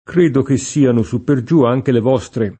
suppergiù [SupperJ2+] o su per giù [id.] avv. — anche sù per giù [id.]: credo che siano sù per giù anche le vostre [
kr%do ke SS&ano Su pper J2 ajke le v0Stre] (Bacchelli) — non supergiù